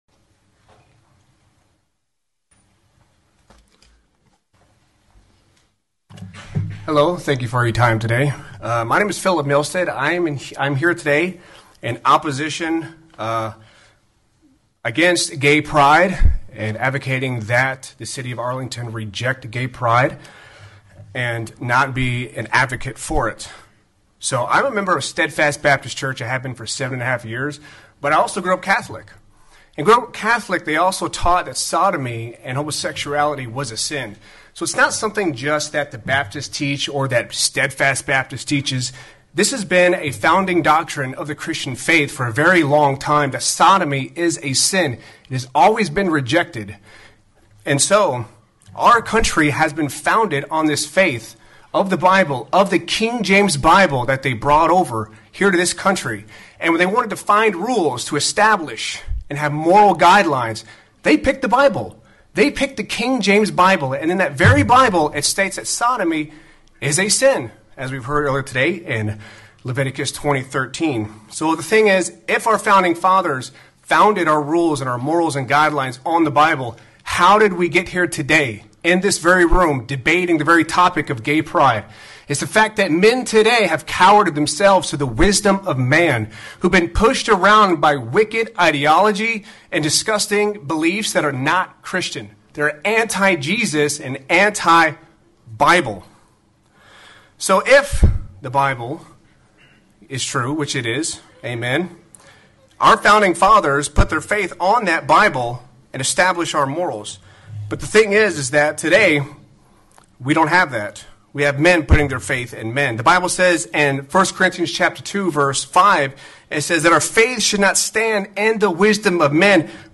Arlington TX City Council Meeting